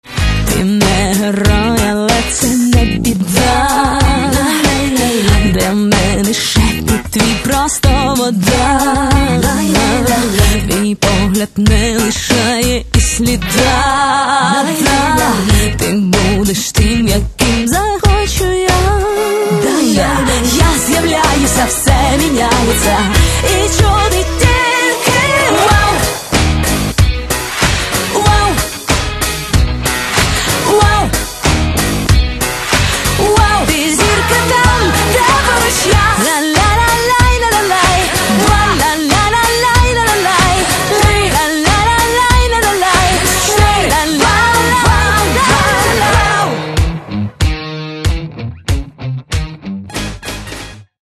Каталог -> Поп (Легкая) -> Подвижная
Альбом записывался в Украине, Швеции и США.